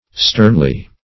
sternly - definition of sternly - synonyms, pronunciation, spelling from Free Dictionary
Sternly \Stern"ly\, adv.